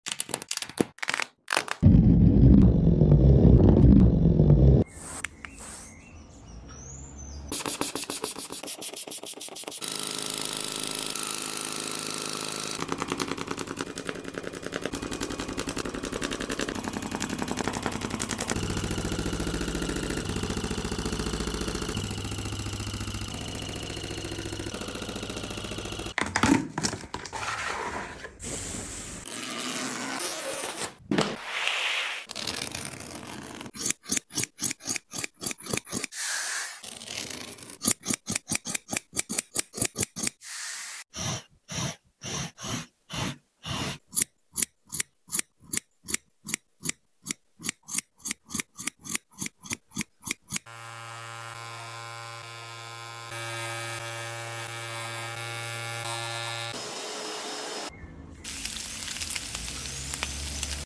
Fried Egg Rug ASMR!